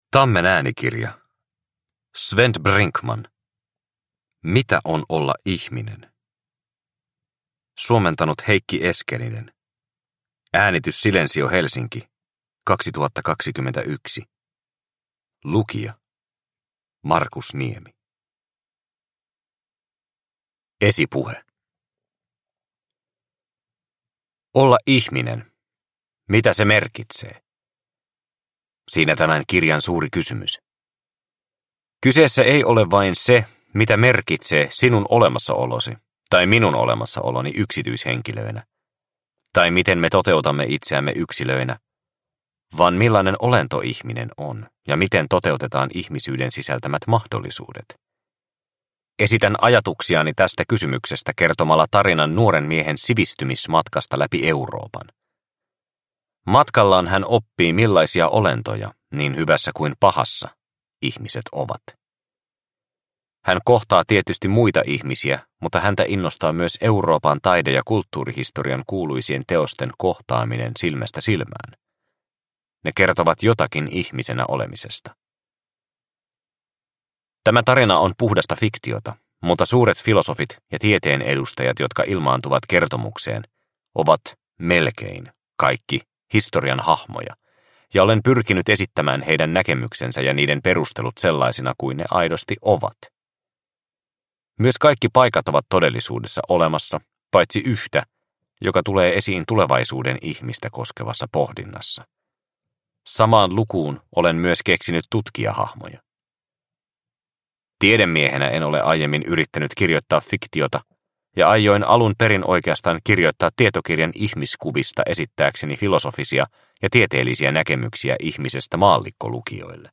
Mitä on olla ihminen? – Ljudbok – Laddas ner